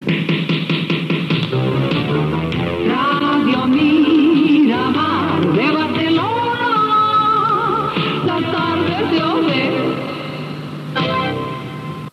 Indicatiu cantat del programa